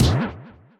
punch.ogg